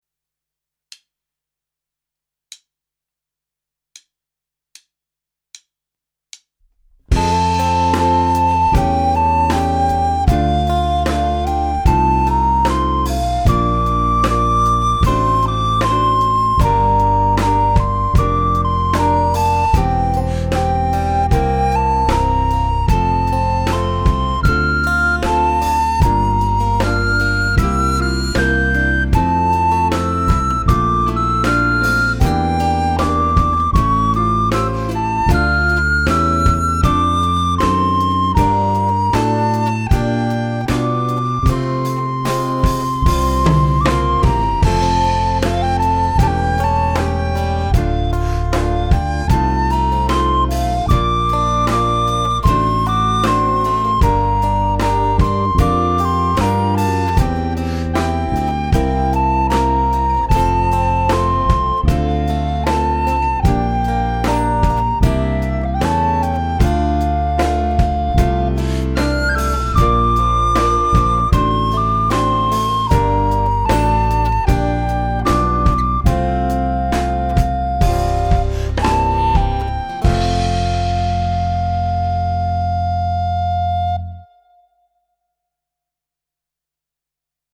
Gattung: Sopranblockflöte/Klavier/CD